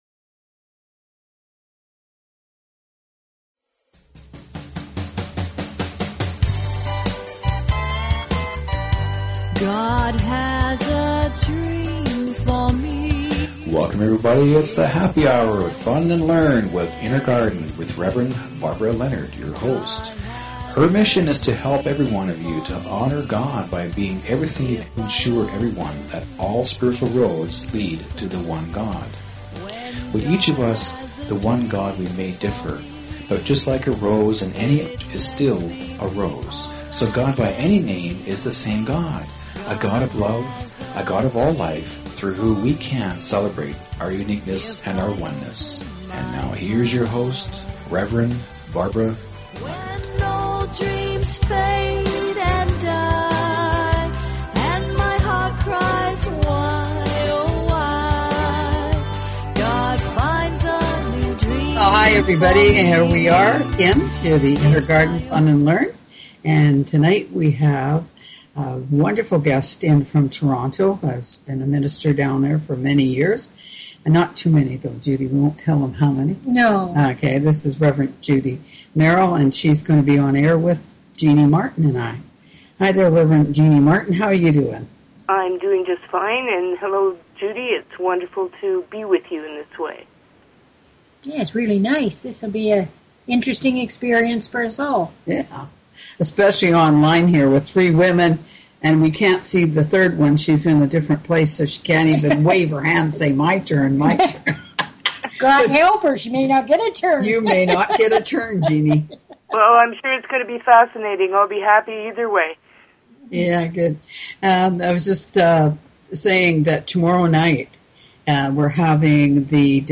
Talk Show Episode, Audio Podcast, Fun_and_Learn_with_Inner_Garden and Courtesy of BBS Radio on , show guests , about , categorized as